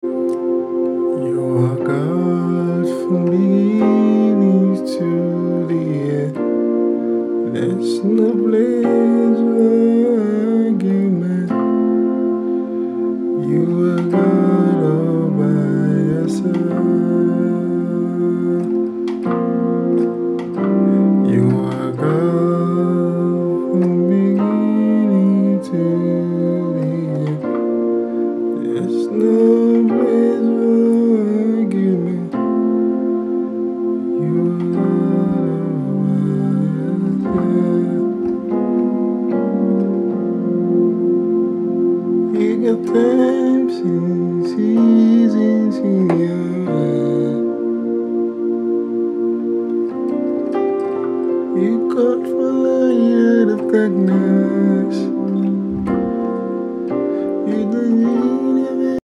simple piano tutorial F major